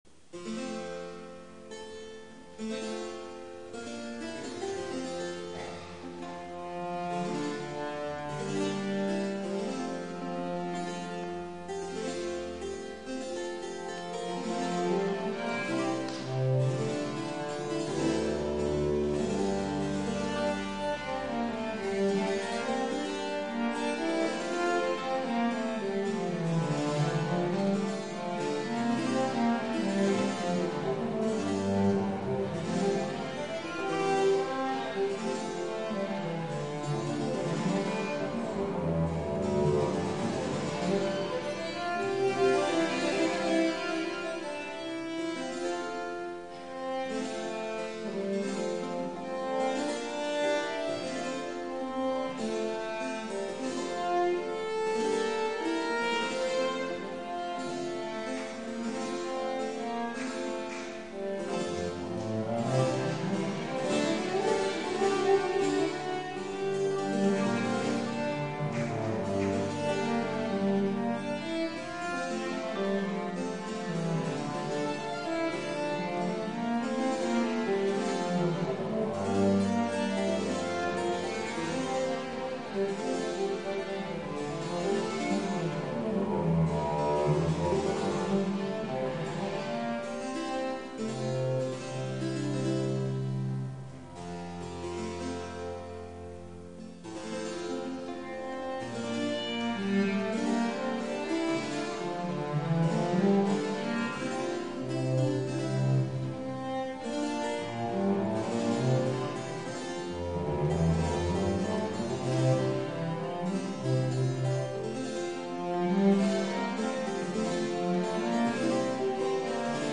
Viola Bastarda
低音域用のViola Bastardaとして調整した
イタリア・タイプのヴィオラ・ダ・ガンバ